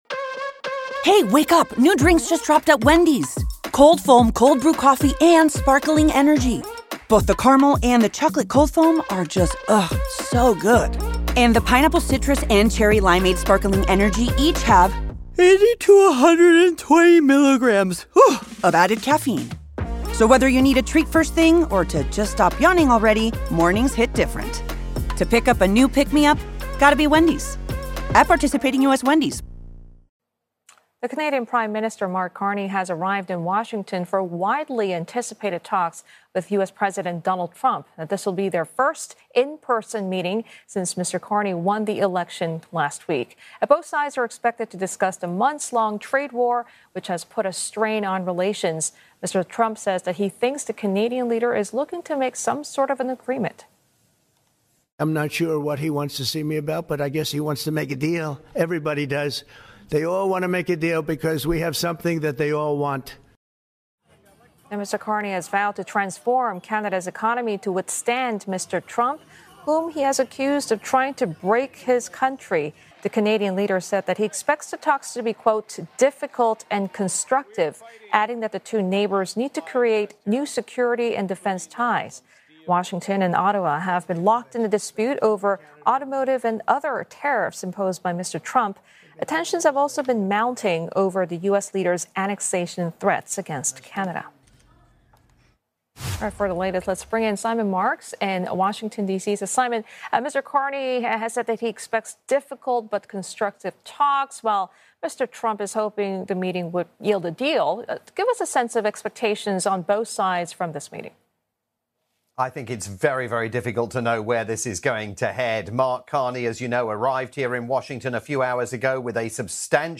live update for Singapore-based pan-Asian tv network CNA